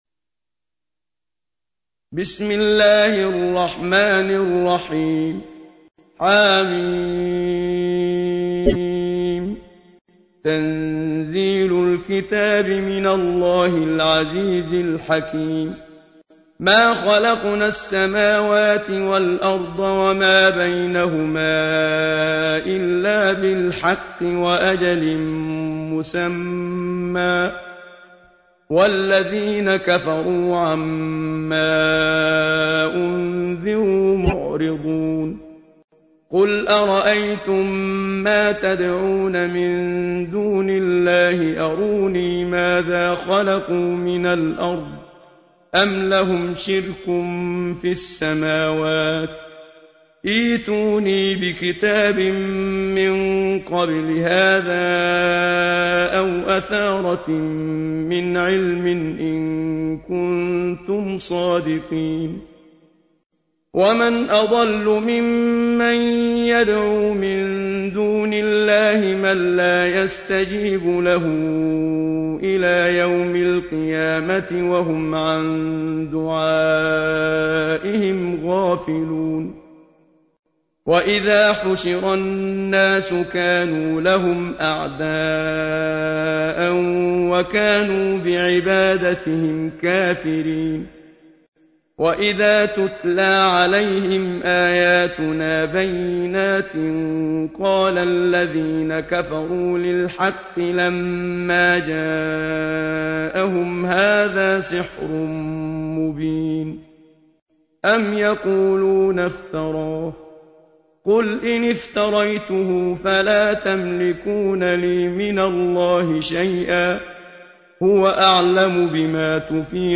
تحميل : 46. سورة الأحقاف / القارئ محمد صديق المنشاوي / القرآن الكريم / موقع يا حسين